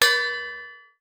Boxing Bell Dull.wav